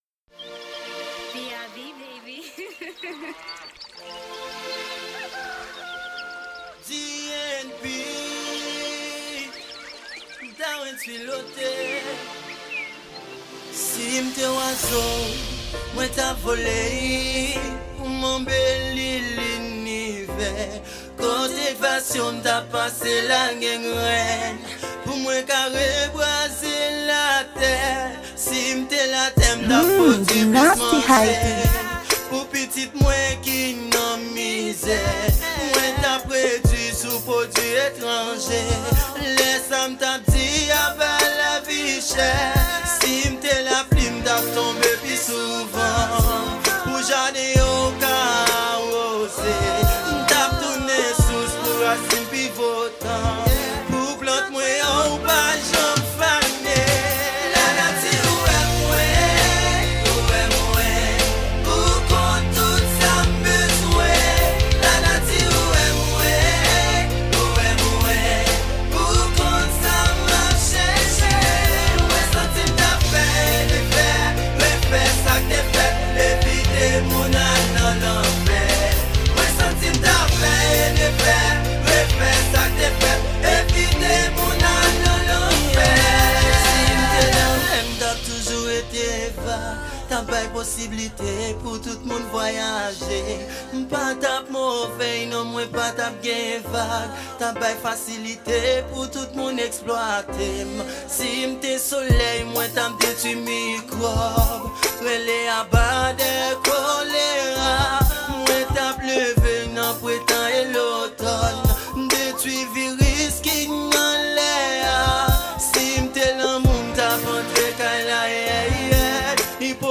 Genre: Rap-Social.